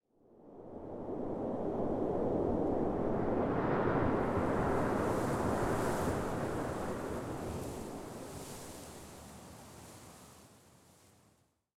housewind06.ogg